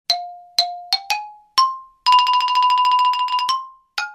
» Small hammers Размер: 68 кб